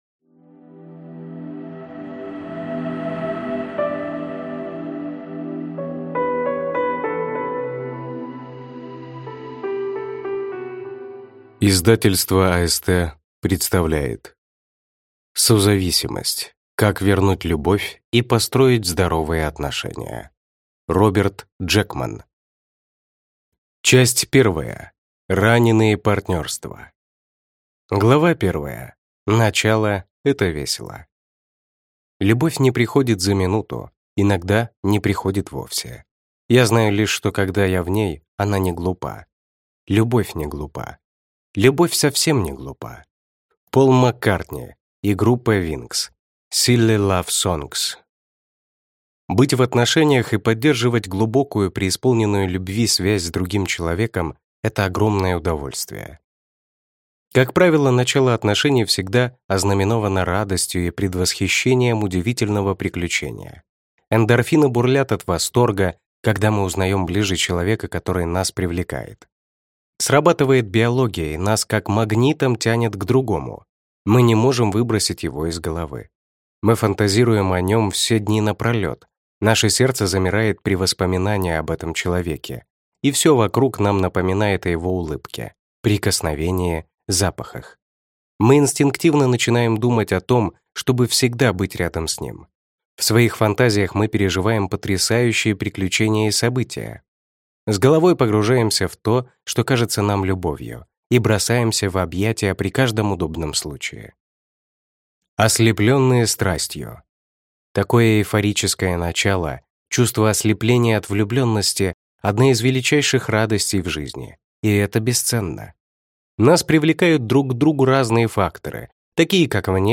Аудиокнига Созависимость. Как вернуть любовь и построить здоровые отношения | Библиотека аудиокниг